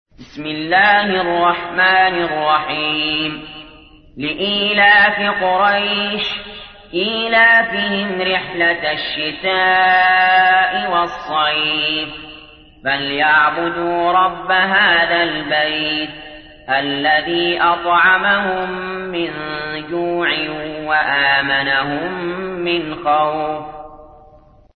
تحميل : 106. سورة قريش / القارئ علي جابر / القرآن الكريم / موقع يا حسين